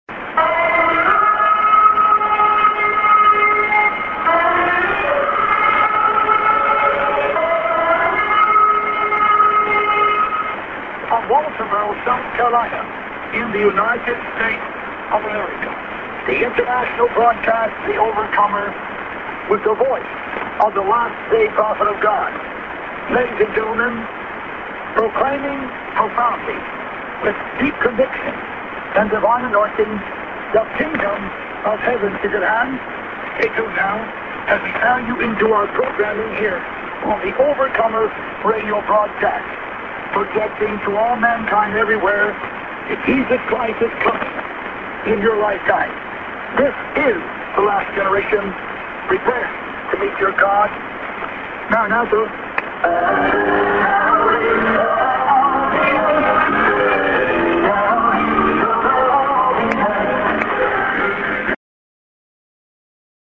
SJ->ADDR+ID(man)->music